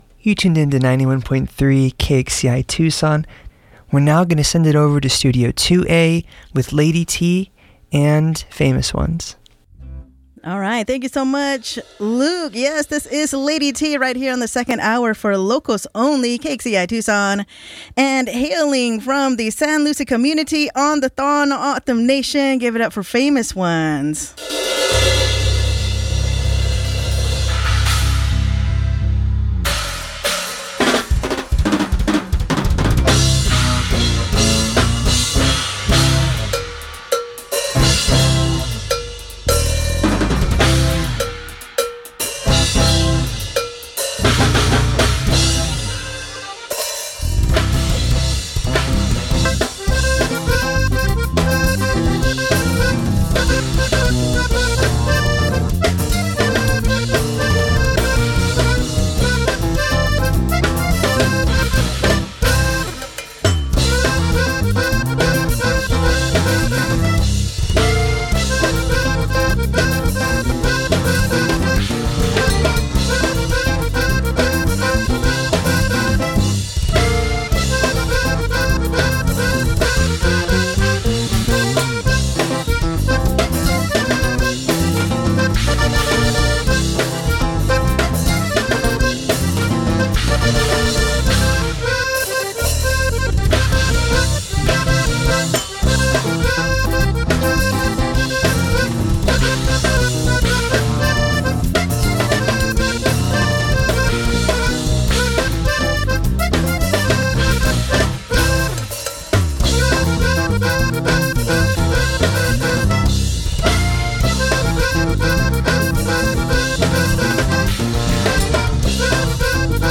live in Studio 2A
Full session recording
Listen to the live performance + interview here!